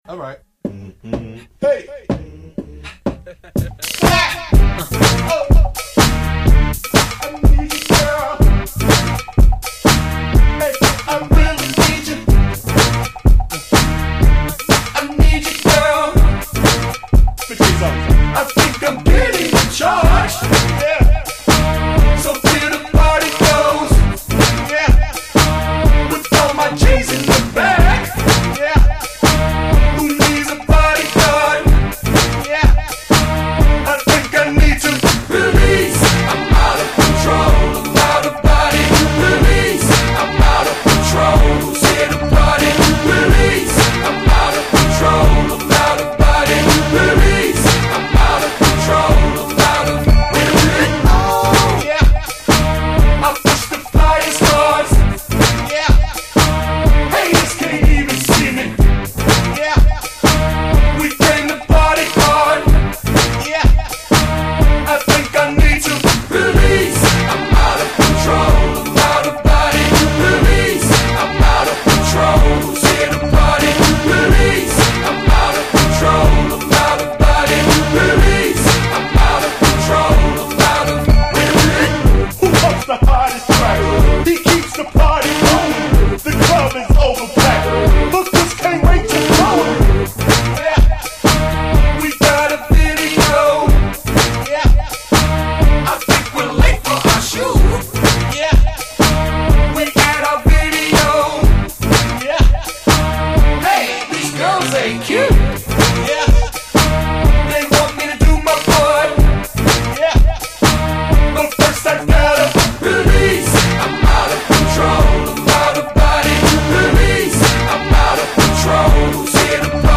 Жанр:Hip-Hop,Rap,R&B,Pop,Rock...